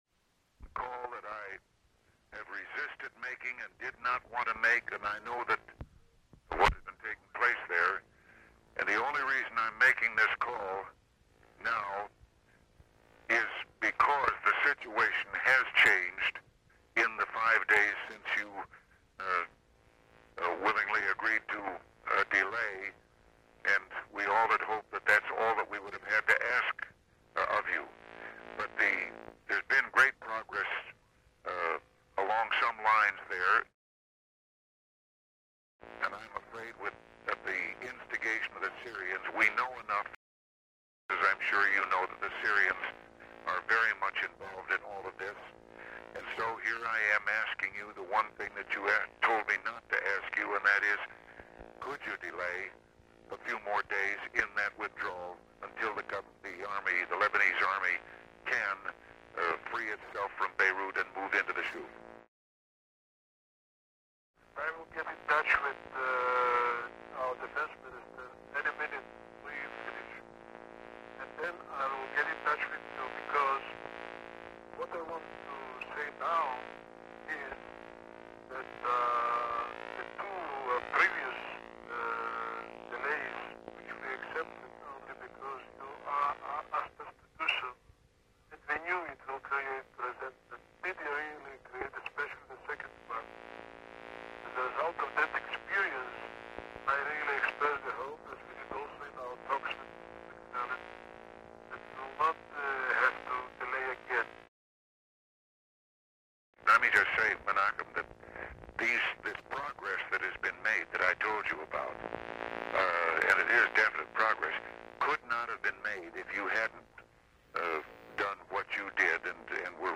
The Presidency / Featured Content A 'Call That I Have Resisted Making' A 'Call That I Have Resisted Making' Photo: Reagan White House Photographs In a telephone call to Israeli prime minister Menachem Begin, President Reagan asked that the Israeli Defense Force delay its withdrawal from positions in Beirut, Lebanon.
Date: September 10, 1983 Location: Situation Room Tape Number: Cassette 1A Participants Ronald W. Reagan Menachem Begin Associated Resources Annotated Transcript Audio File Transcript